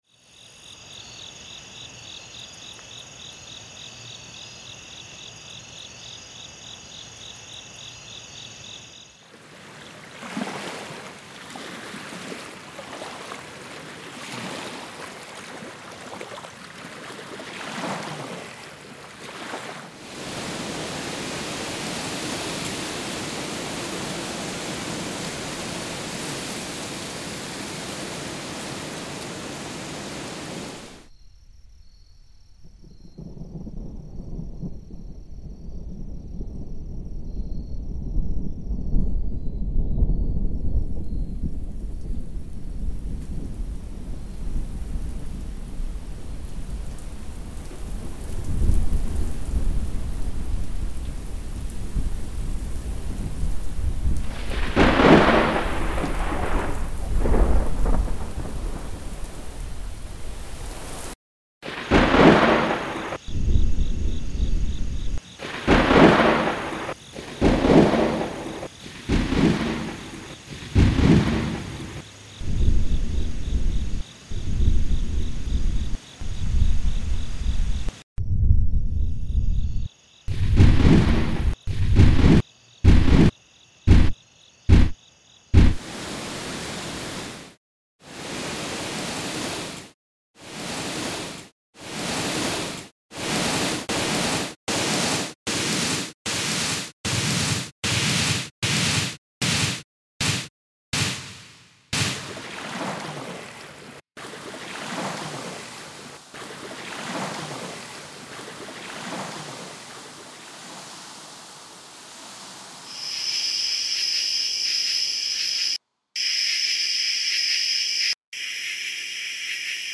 I had taken ambiances and spliced them, EQ’d them, and other stuff and managed to create a kick drum sound out of thunder, snare out of wind, symbols out of a river, and a shaker from cicadas. But once it got going, it just wasn’t fun or pleasant to listen to.
The sound is a little harsh in places, I obviously didn’t want to waste more time finessing it.
recorded one of the thunder and rain sound effects in there… those are hard to catch in the city